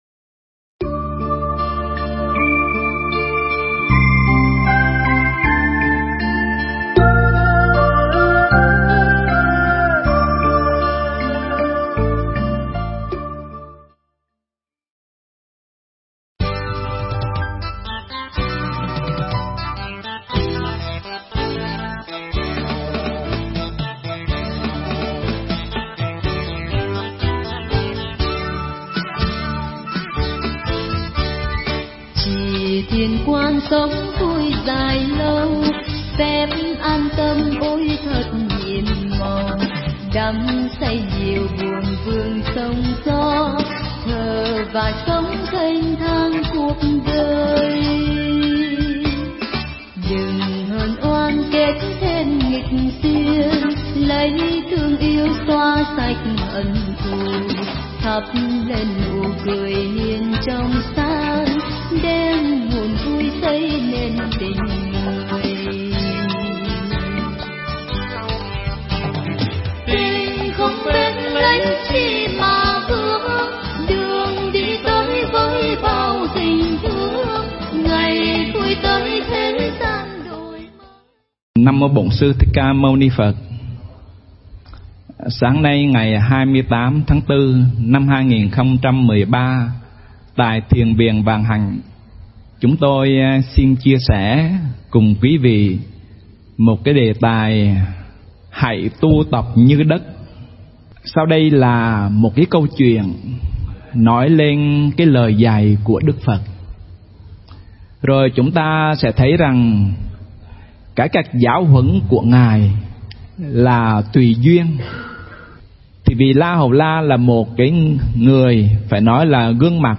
Nghe Mp3 thuyết pháp Hãy Tu Tập Như Đất